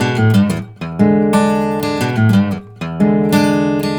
Index of /90_sSampleCDs/ILIO - Fretworks - Blues Guitar Samples/Partition G/120BARI RIFF